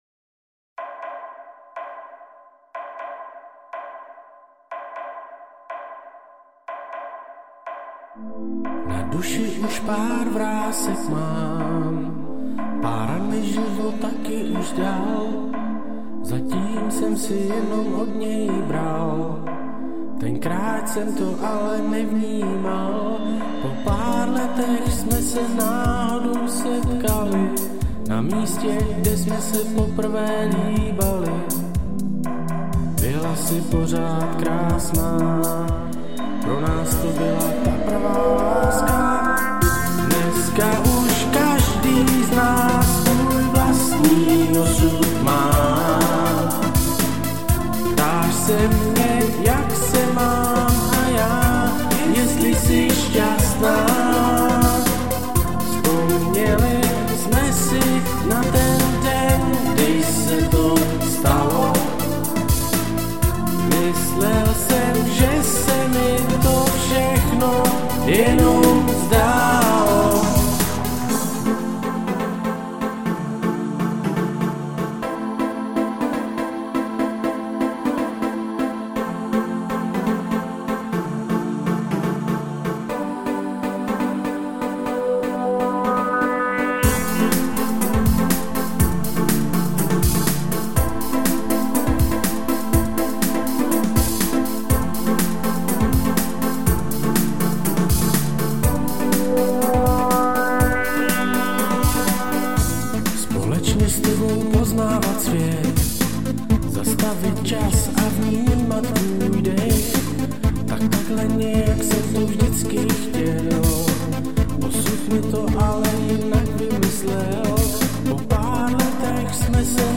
Žánr: Electro/Dance
místy zase spíš zklidňující.